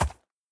horse_soft1.ogg